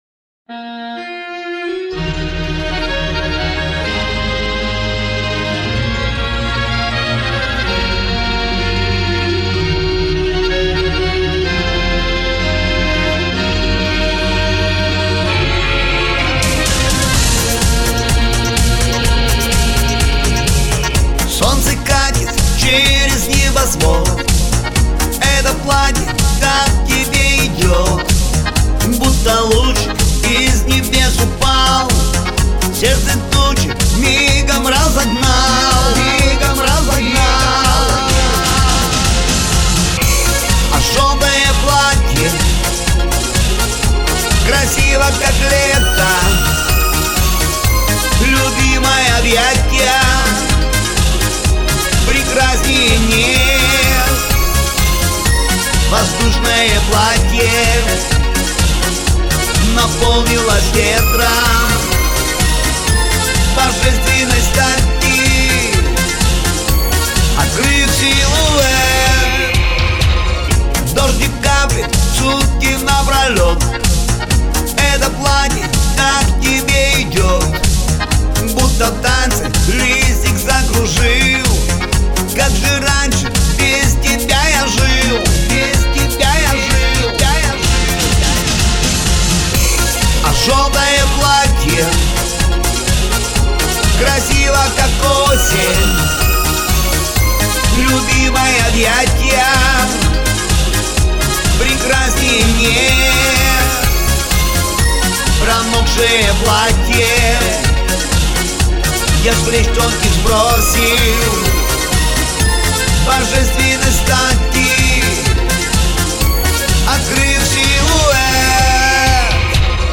Поп музыка